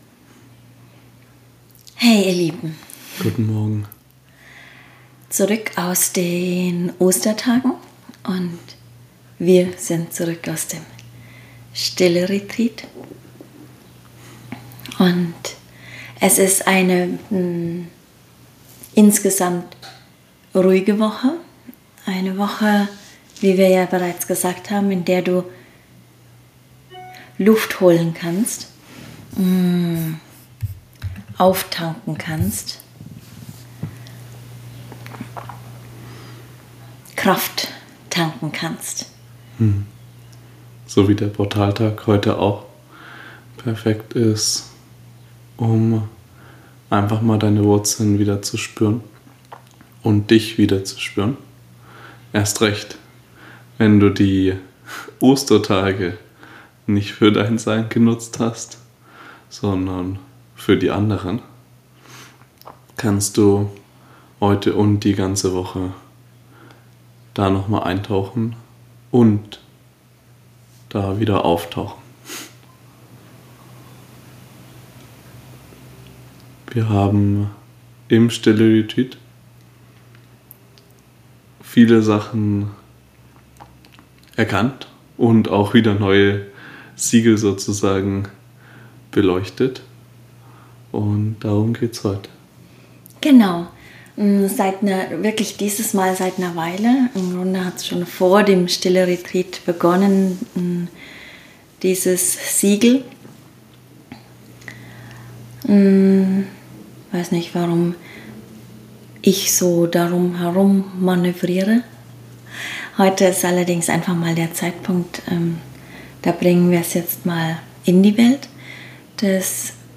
Channeling | Siegel der Spiritualität ~ MenschSein - musst du leben.
Wie immer channeln wir aus dem kristallinen GitterNetz.